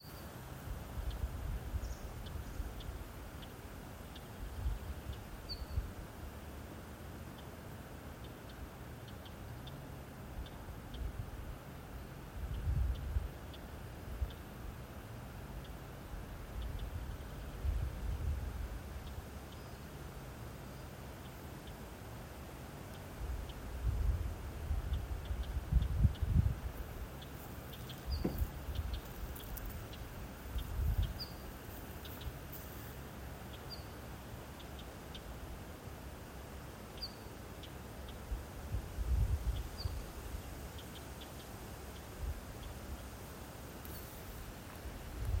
Putni -> Mežastrazdi ->
Akmeņčakstīte, Oenanthe oenanthe
StatussUztraukuma uzvedība vai saucieni (U)